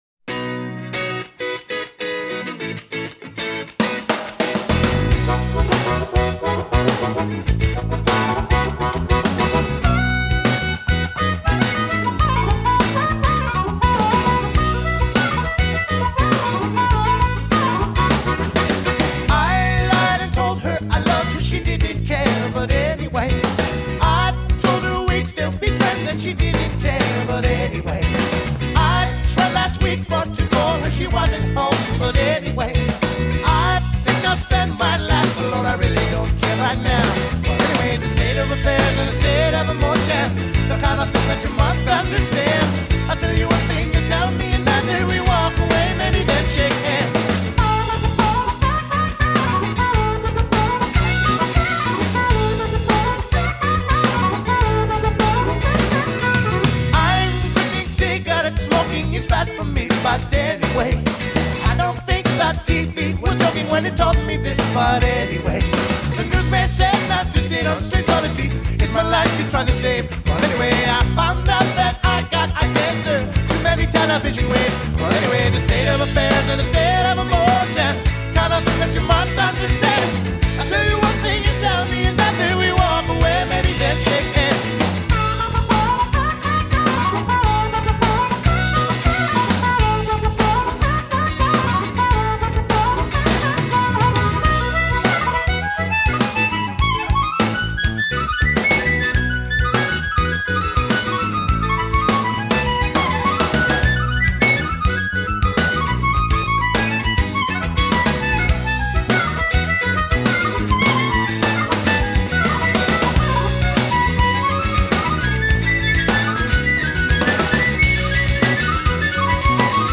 vocals, harmonica, 12-string acoustic guitar
bass
drums, percussion
soprano saxophone
piano
handclaps
background vocals